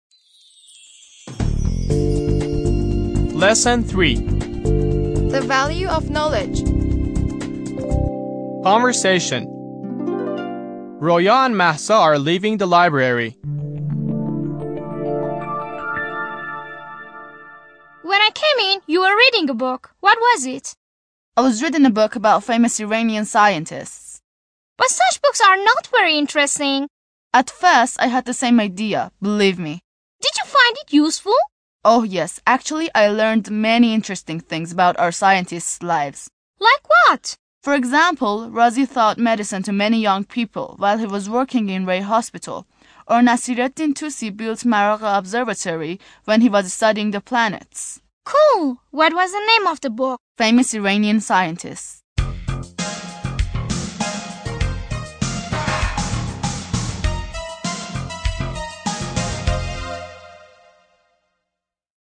10-L3-Conversation